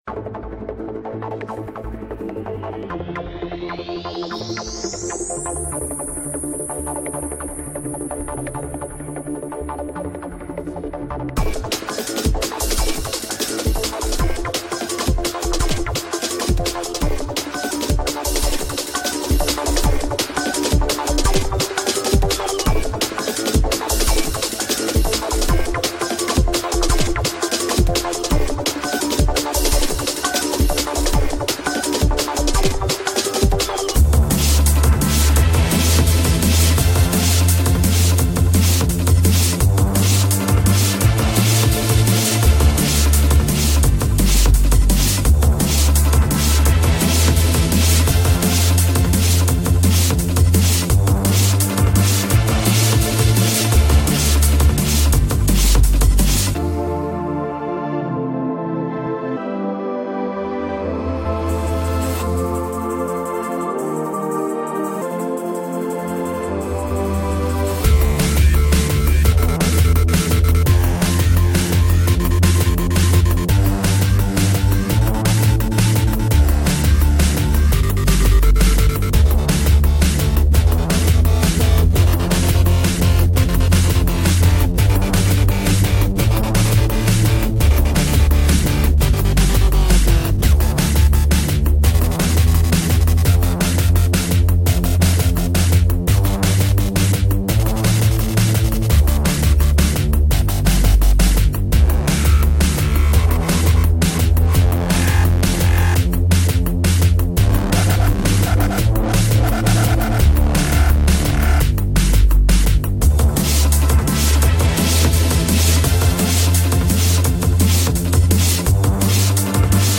King_of_Bass_Music_ENergy_Trance_DNB.mp3